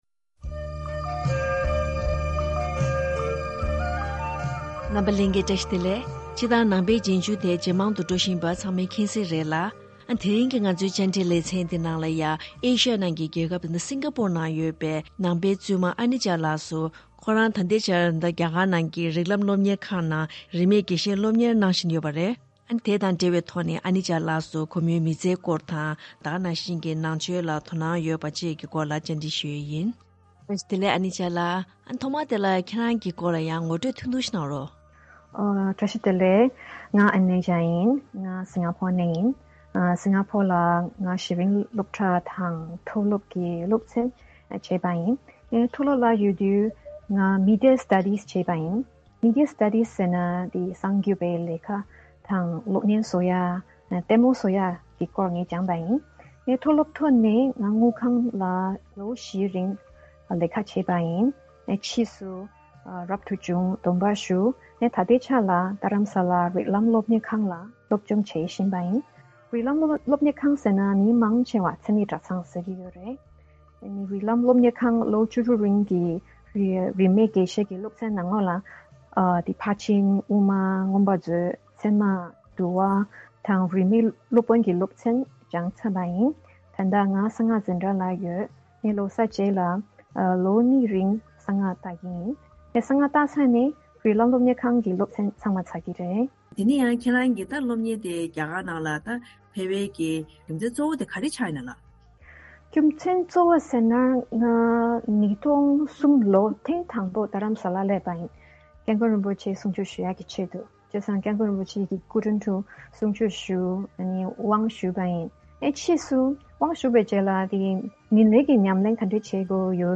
བཅར་འདྲི་ལེ་ཚན་འདིའི་ནང་།